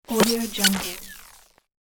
دانلود افکت صدای خاموش کردن اختلال فضایی
• صداهای خاموش کردن نویزهای استاتیک و تداخلات سیگنال
• صداهای قطع و وصل ارتباط رادیویی
16-Bit Stereo, 44.1 kHz